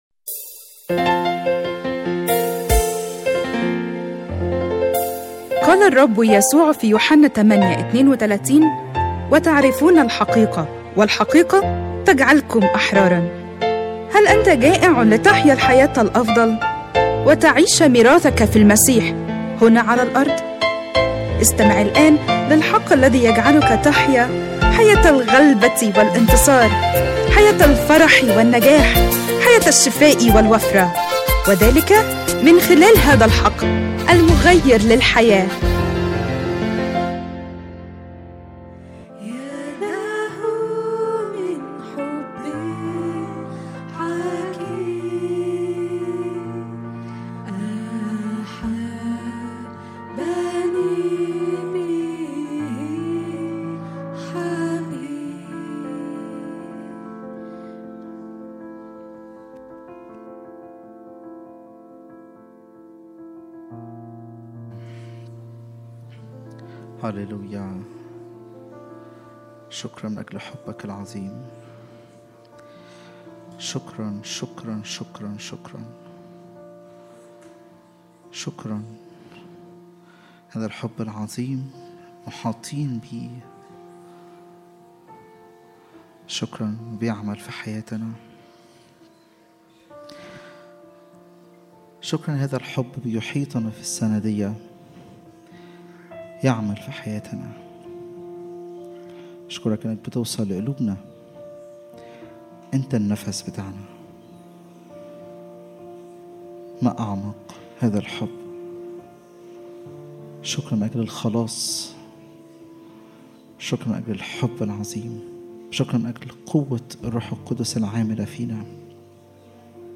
اجتماع الثلاثاء